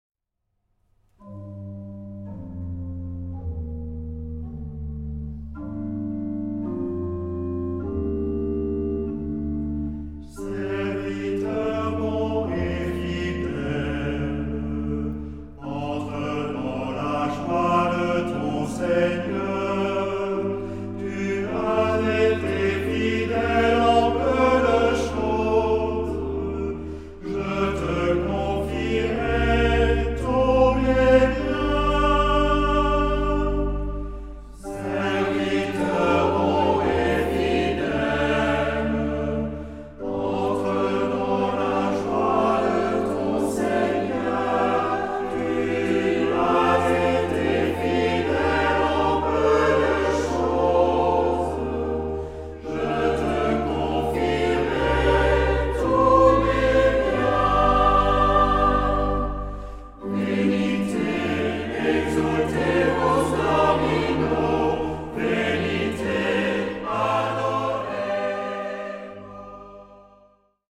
Genre-Style-Forme : Sacré ; Tropaire ; Psaume
Caractère de la pièce : recueilli
Type de choeur : SATB  (4 voix mixtes )
Instruments : Orgue (1)
Tonalité : sol mineur